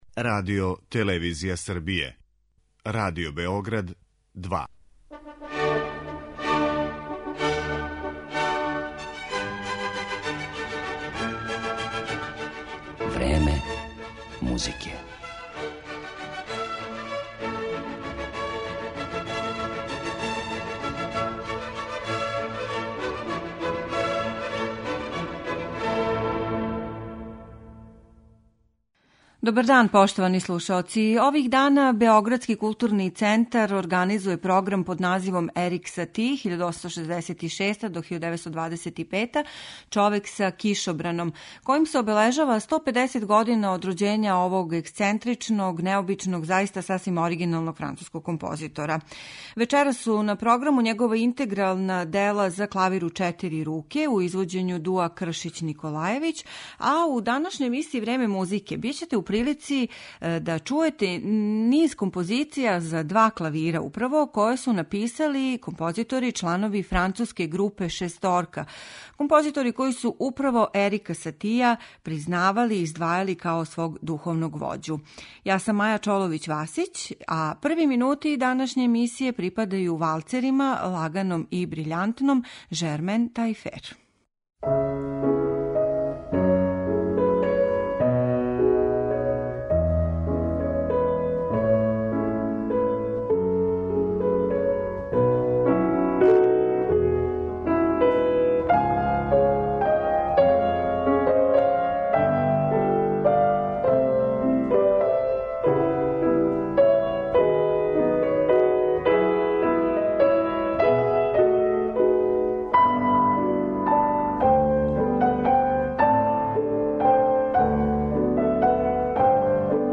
за два клавира